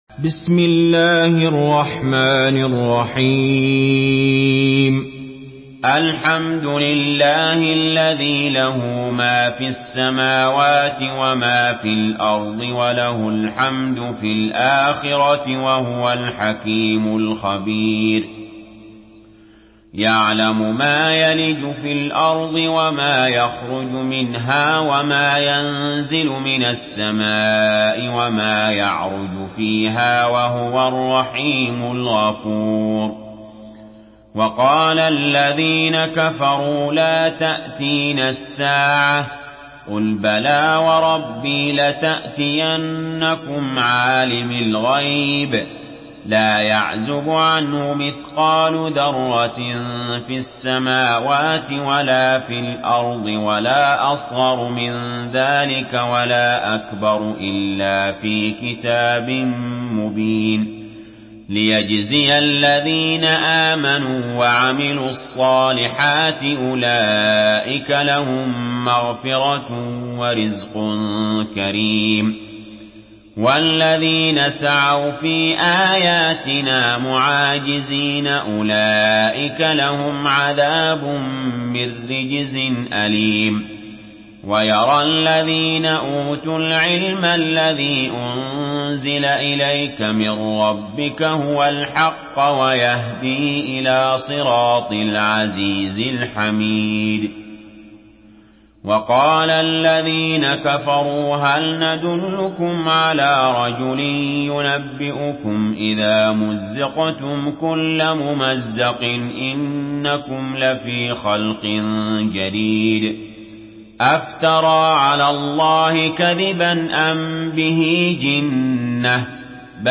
Surah Sebe MP3 by Ali Jaber in Hafs An Asim narration.
Murattal Hafs An Asim